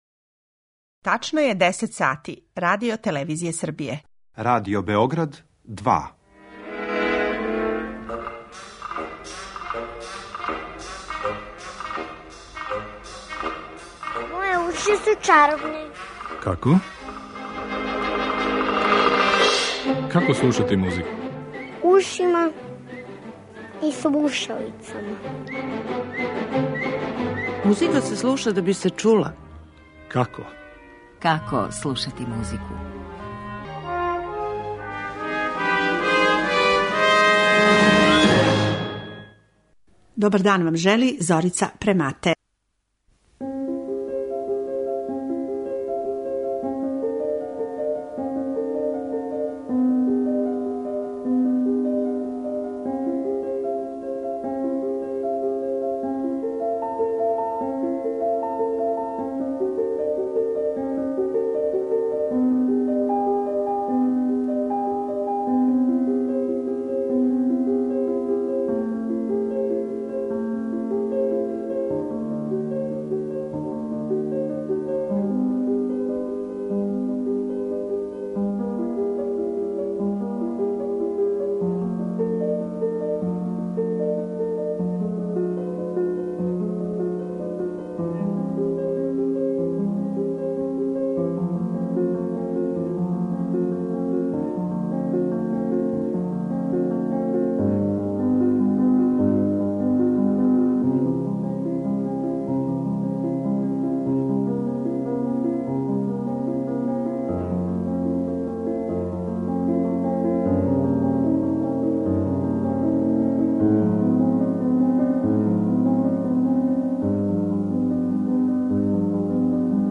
Гошћа циклуса емисија 'Како слушати музику' је музиколошкиња